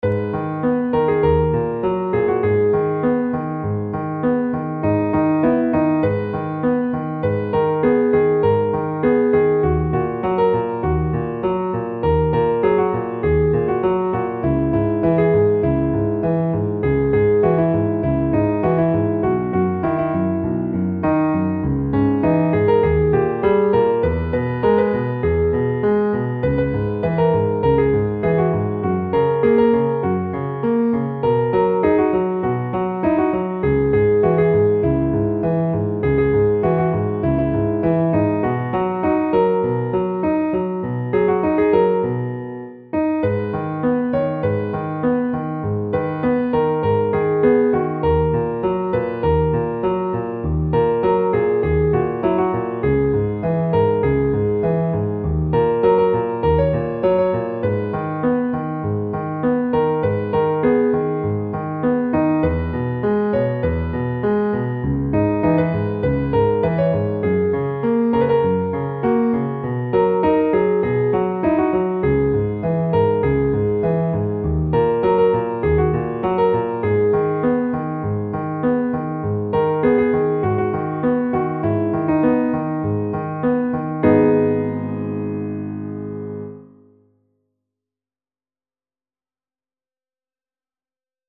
نت پیانو
این محصول شامل دو نسخه (گام اصلی + گام ساده) می باشد